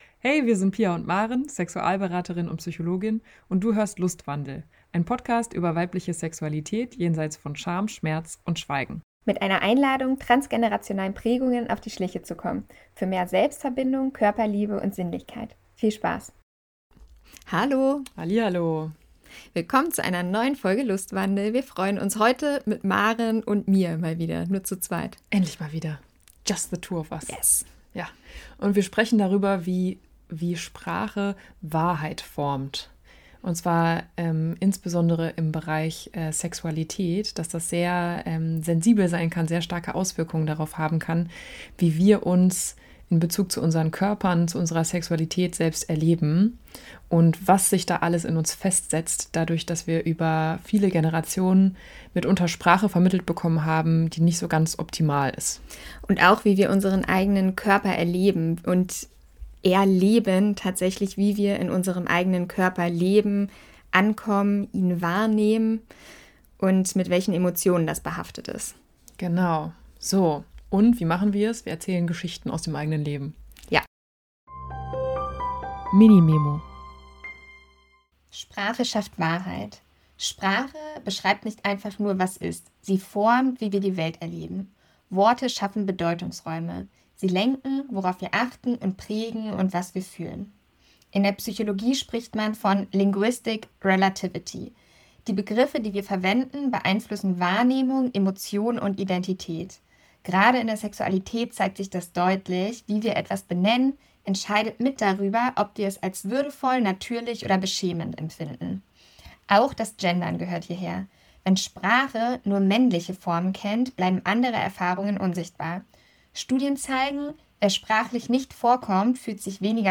Wie werden weibliche Körper sprachlich abgewertet – und was braucht es, um unser eigenes Lustlexikon zu erneuern?Ein Gespräch über Sprache, Macht und die feinen Linien zwischen Scham und Würde.